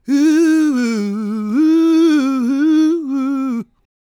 GOSPMALE205.wav